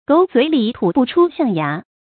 成語注音ㄍㄡˇ ㄗㄨㄟˇ ㄌㄧˇ ㄊㄨˇ ㄅㄨˋ ㄔㄨ ㄒㄧㄤˋ ㄧㄚˊ
成語拼音gǒu zuǐ lǐ tǔ bù chū xiàng yá
狗嘴里吐不出象牙發音